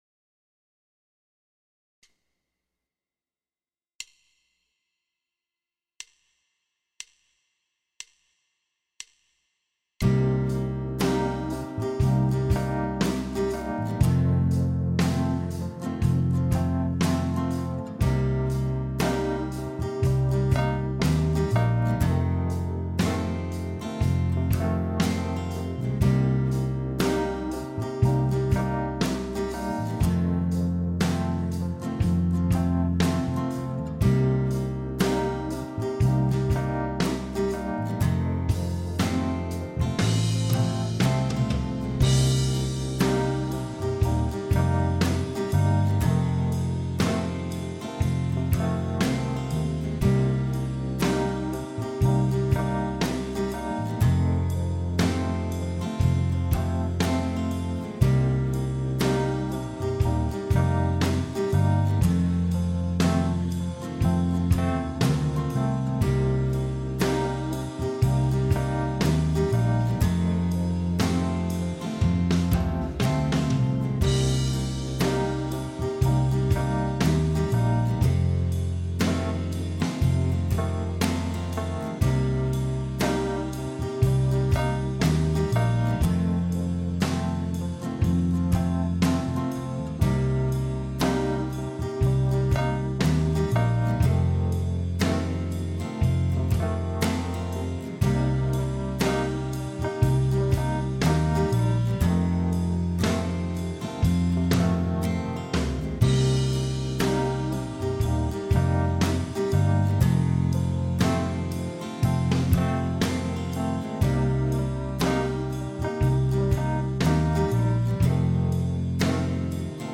(David Gilmour style)